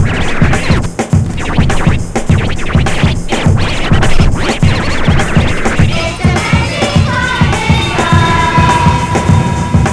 funk
pounding journey